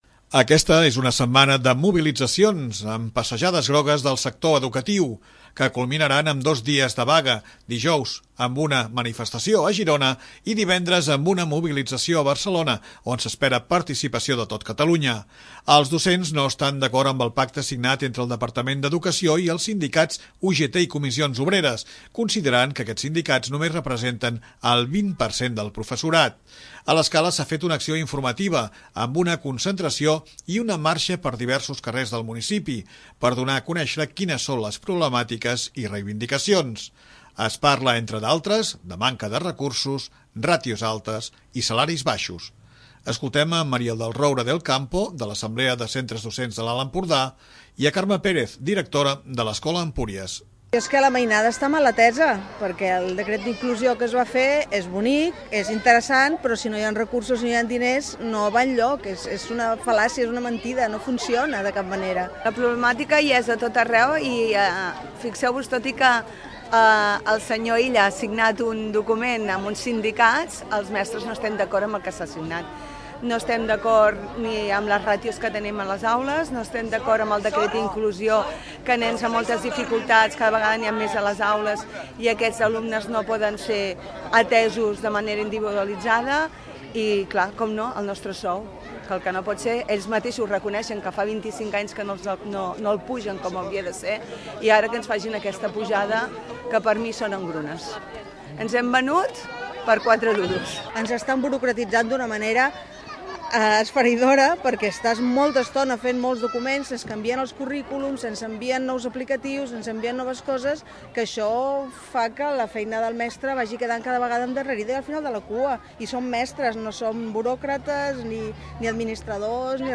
A l'Escala, s'ha fet una acció informativa, amb una concentració i una marxa per diversos carrers del municipi, per donar a conèixer quines són les problemàtiques i reivindicacions.
Durant l'acció s'han pogut escoltar consignes com “Educació de qualitat”, “Per la inclusió un caleró” o “Comissions i UGT són uns traïdors”.